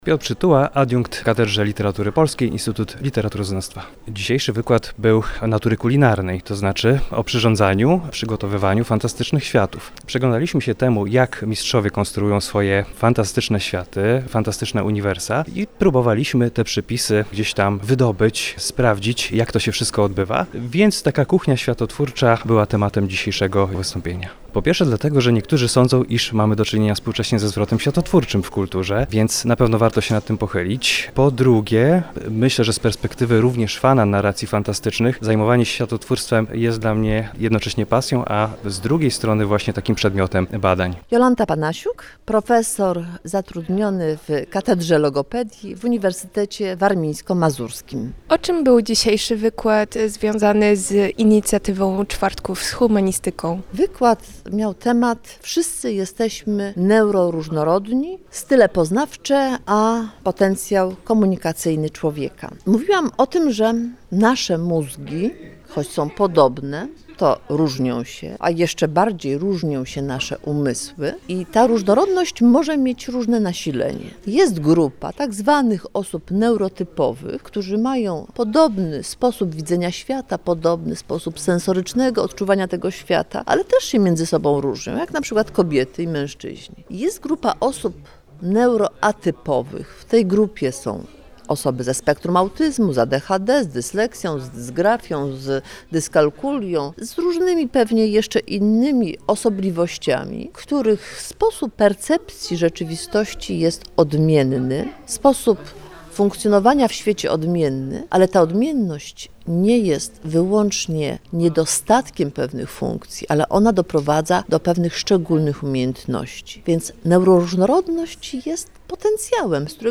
rozmawiała także z prelegentami grudniowych Czwartków z Humanistyką.